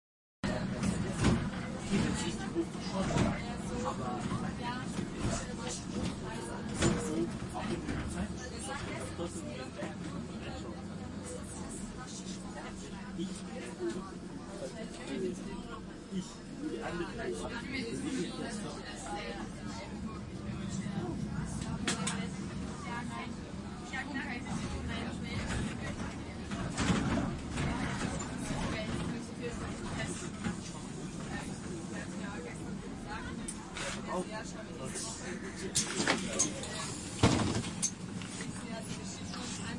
夜晚的旅游巴士之旅
晚上2点50分用索尼Xperia XZ1紧凑型麦克风录音。我们在里昂附近，巴士行驶约90公里/小时。可以不时听到轻微的打鼾声。
标签： 旅行 旅行 巴士车 旅游巴士 教练之旅 打鼾 夜间行程
声道立体声